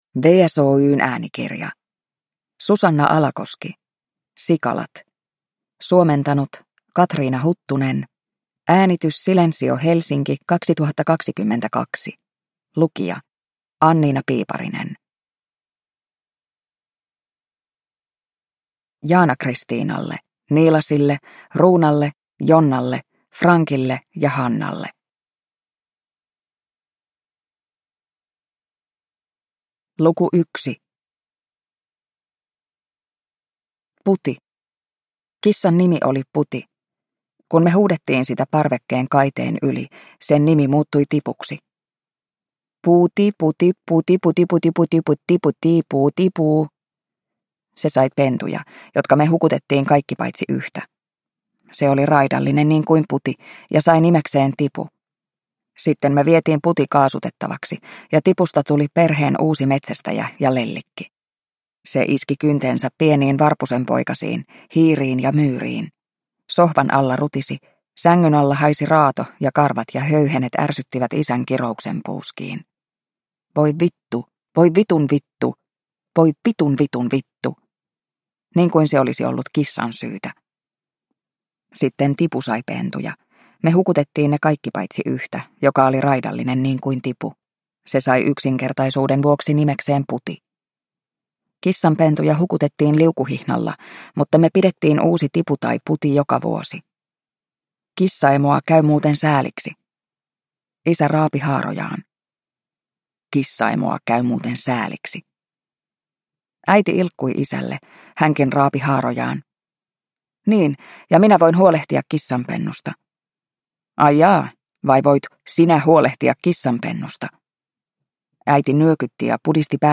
Sikalat – Ljudbok – Laddas ner
Uppläsare: